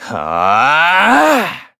Kibera-Vox_Casting4_kr.wav